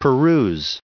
577_peruse.ogg